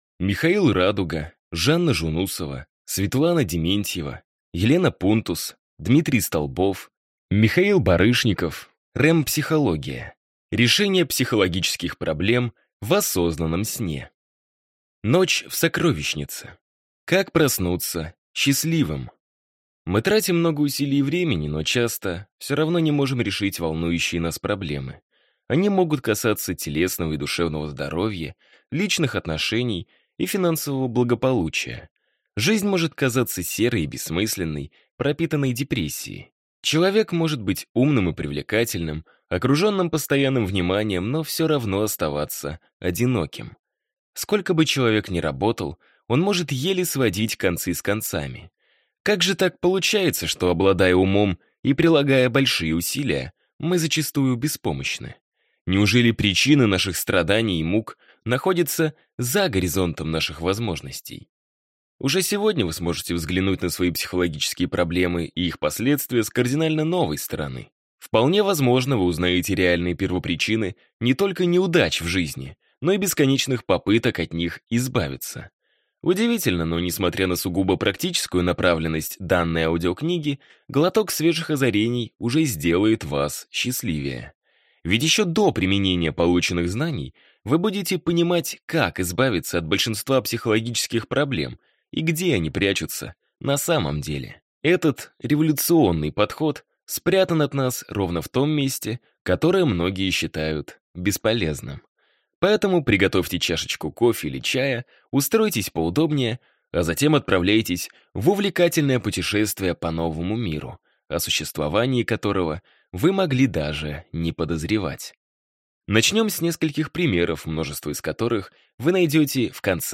Аудиокнига REM-психология. Решение психологических проблем в осознанном сне | Библиотека аудиокниг